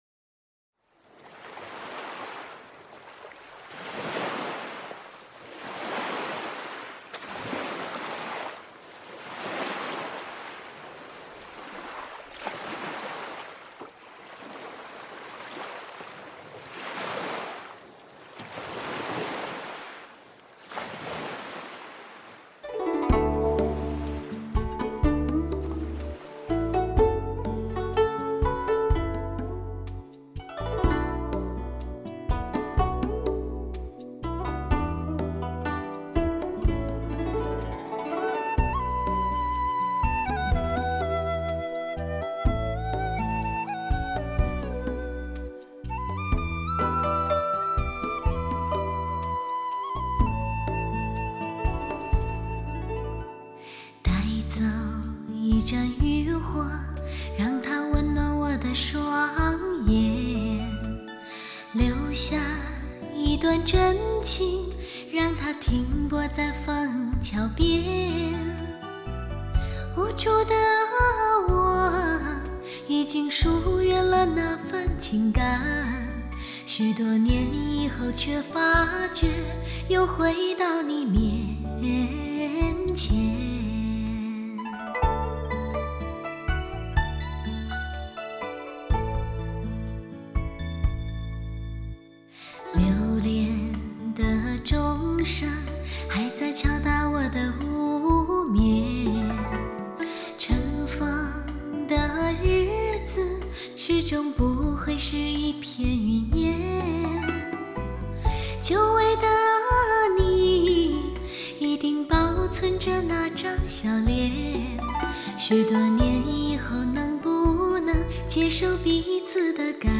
唯美天籁之声，深情刻画出感悟、感性的心声。
挥晒，歌声与器乐于巧夺天工的辉映中激荡出惊艳四座的动人乐音。